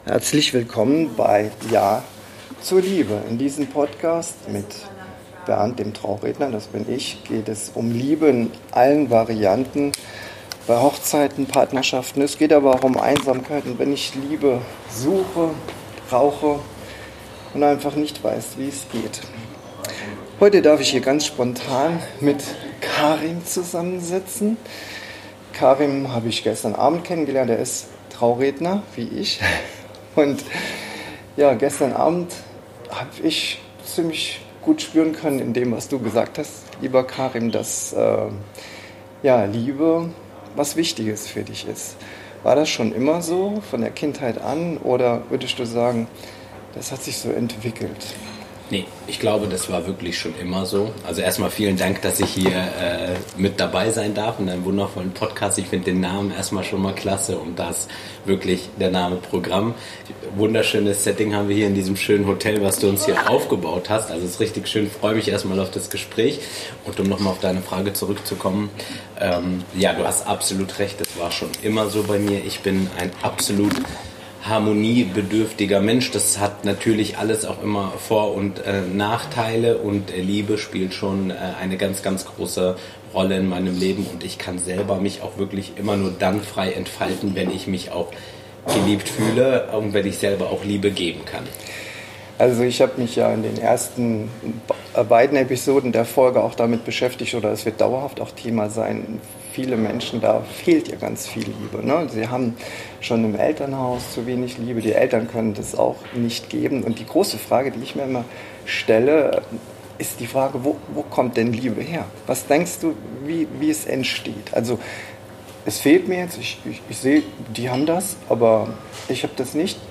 Braucht Liebe Demut? - Gespräch